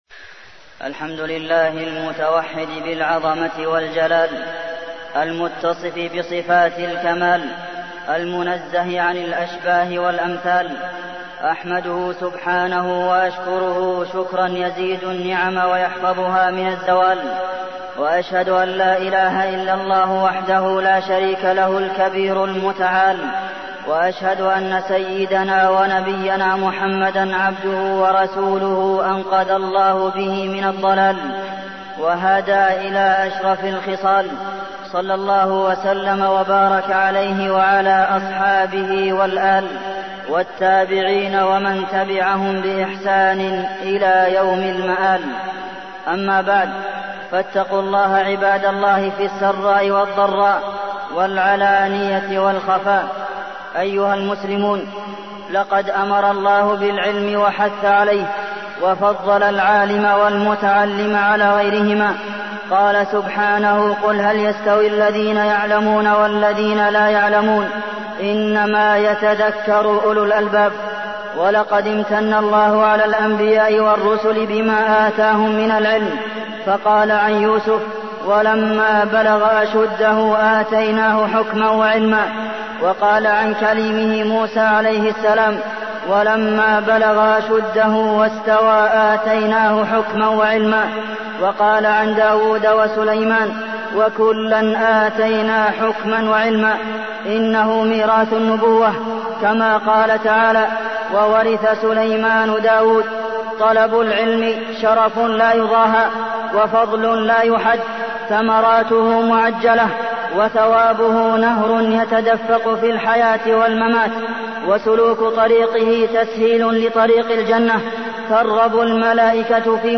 تاريخ النشر ٢٨ محرم ١٤٢٠ هـ المكان: المسجد النبوي الشيخ: فضيلة الشيخ د. عبدالمحسن بن محمد القاسم فضيلة الشيخ د. عبدالمحسن بن محمد القاسم فضل العلماء ووفاة الشيخ بن باز